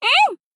BA_V_Mutsuki_Battle_Shout_1.ogg